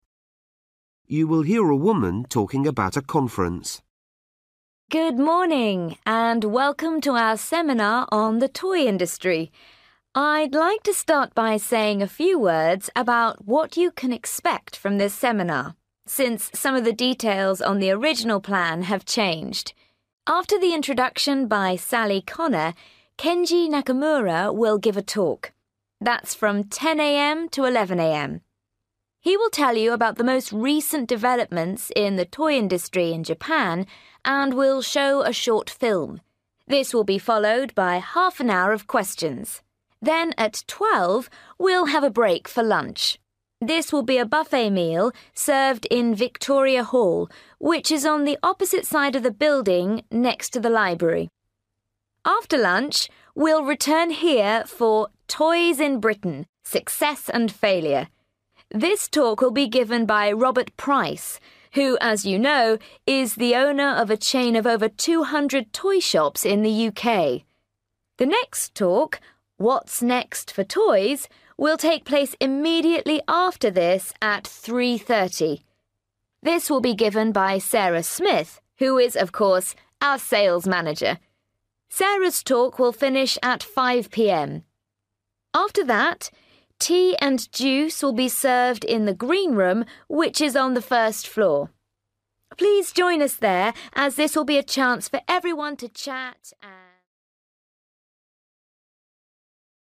You will hear a woman talking about a conference.